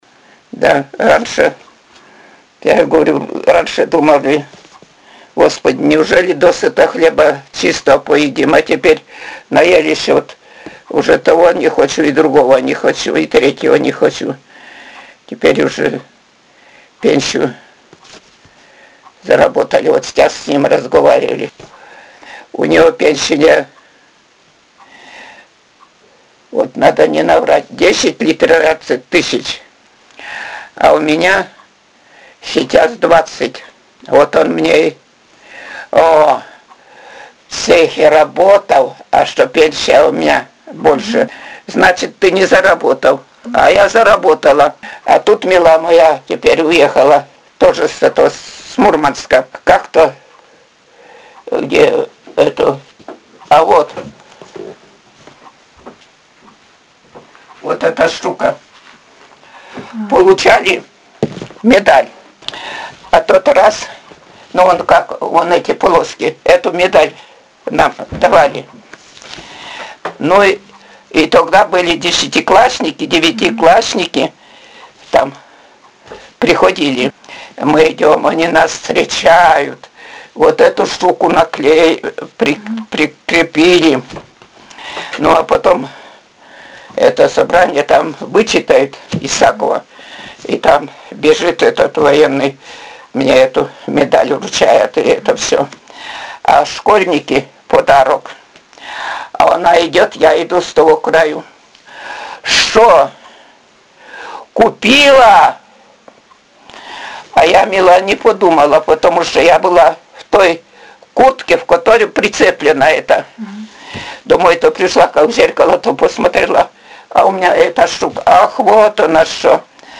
— Говор северной деревни
Пол информанта: Жен.
Аудио- или видеозапись беседы: